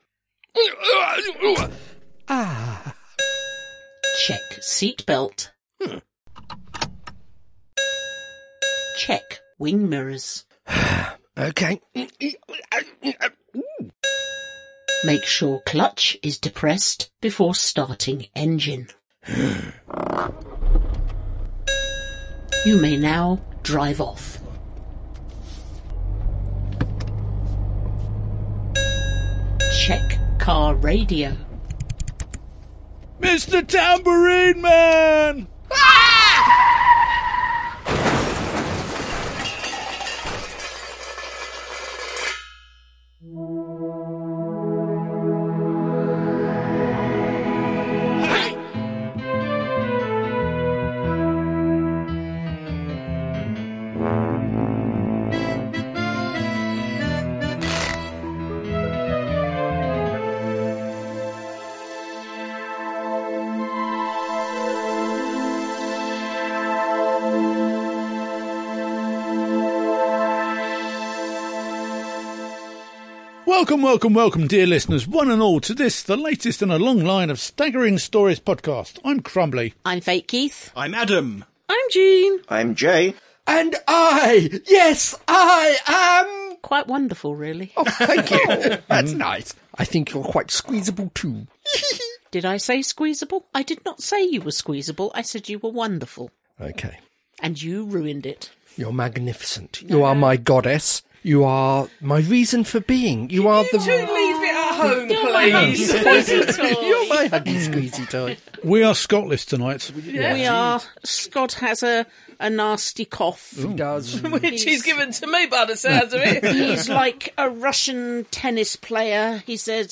Intro and theme tune.
End theme, disclaimer, copyright, etc.